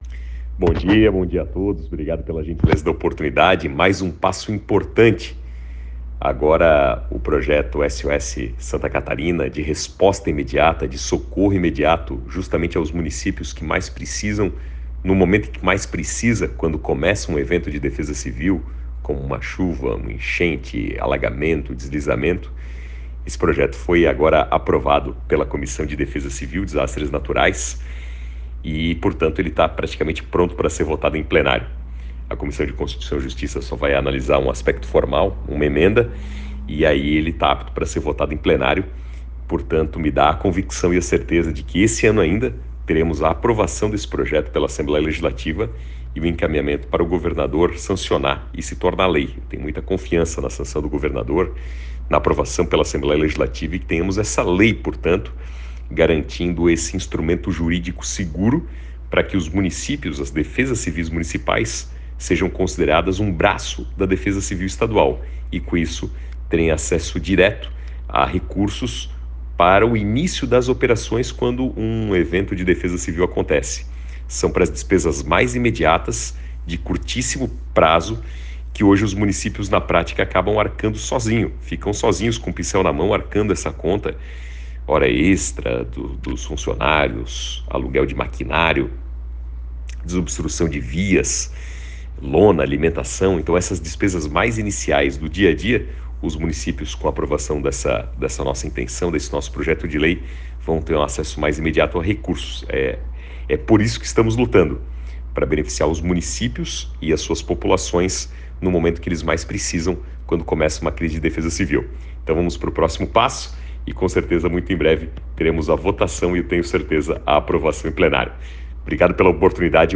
Áudio do deputado Napoleão Bernardes